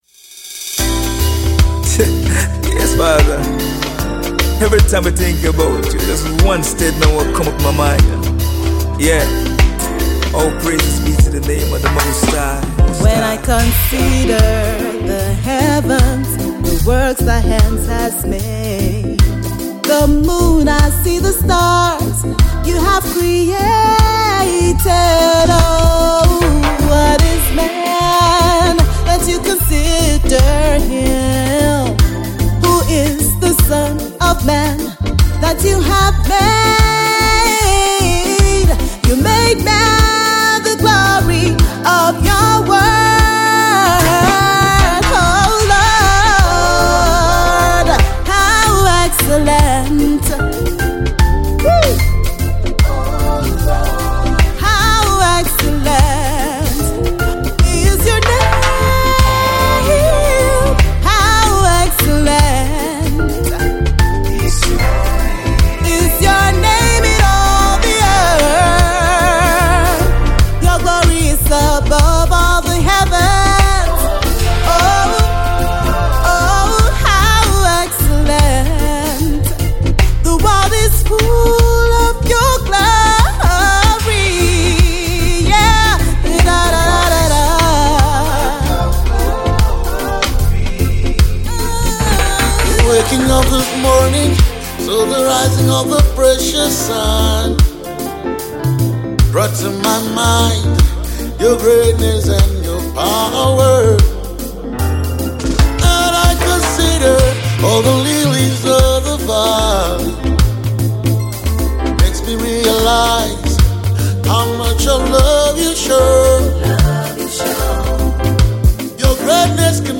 Sensational gospel singer